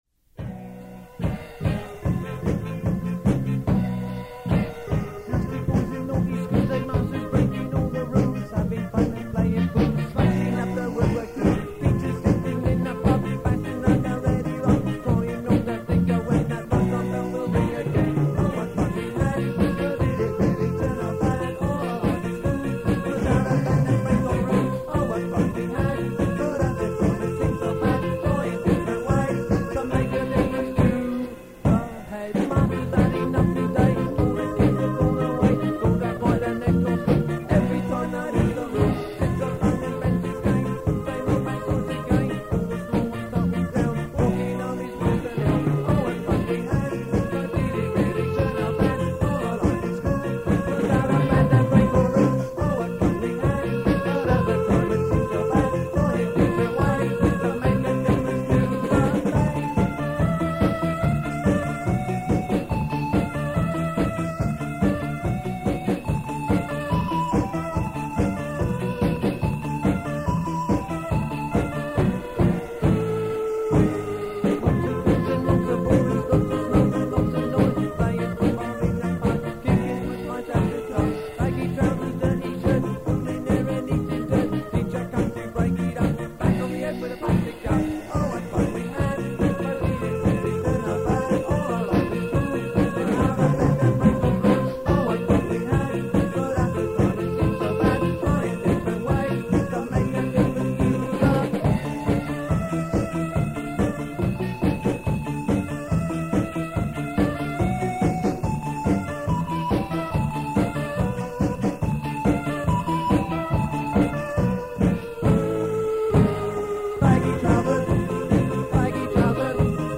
Keep in mind that these are from very old cassette tapes, so sound quailty is definitely an issue.
written by Madness: from the Rehearsal tape